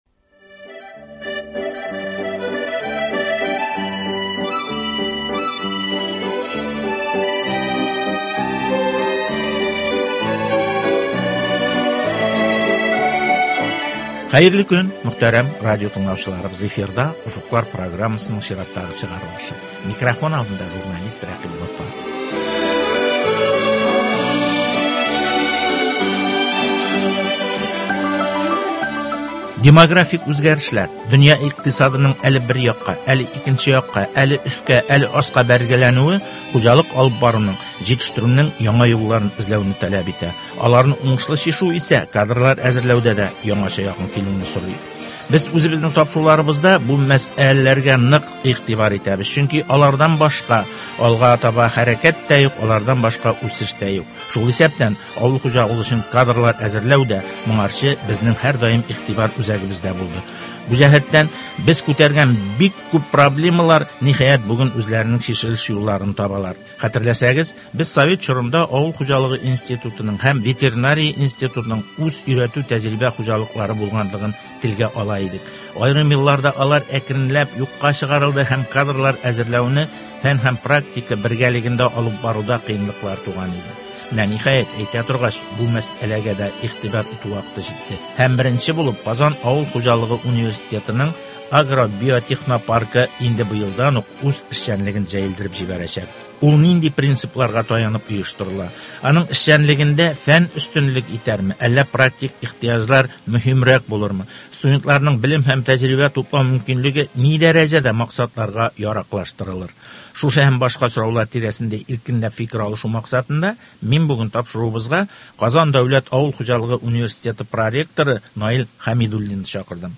җентекле әңгәмә.